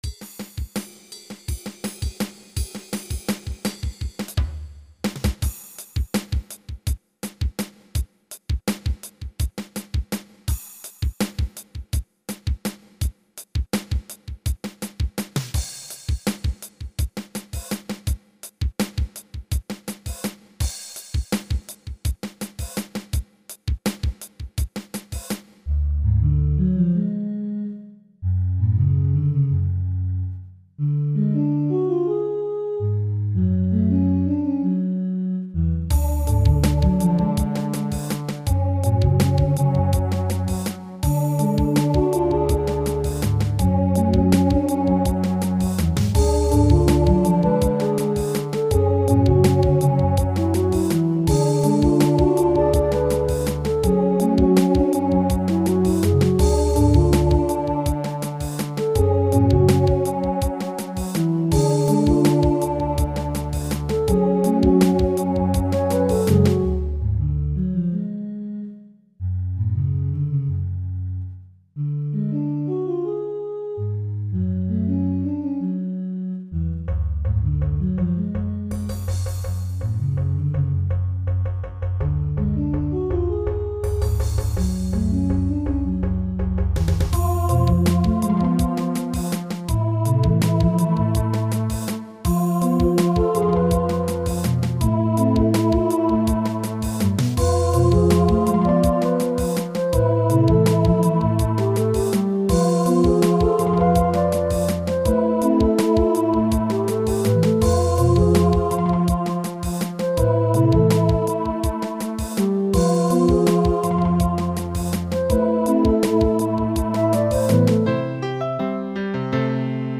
This was originally a MIDI file from September of 2004. Most of it is in 7/8 time.